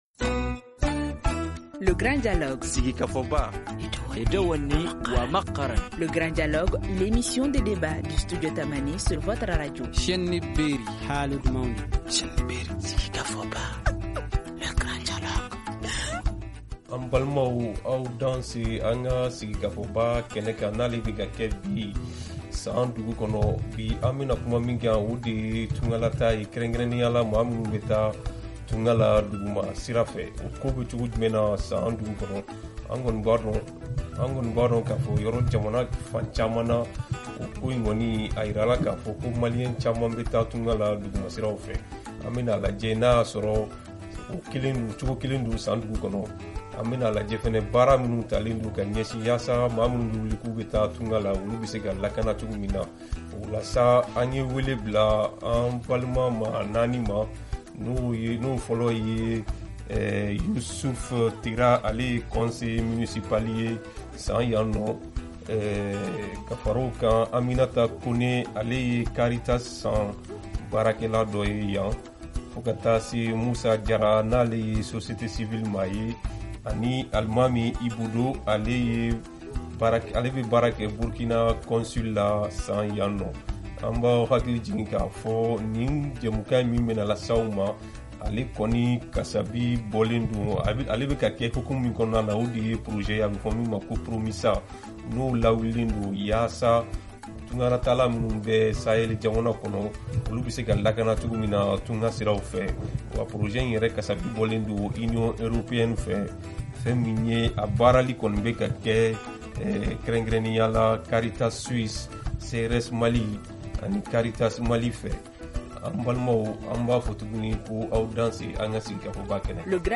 C’est le thème de notre grand dialogue d’aujourd’hui délocalisé dans la ville de San.
Studio Tamani pose le débat ici à la radio Prarana avec nos invités. Ils sont au nombre de quatre :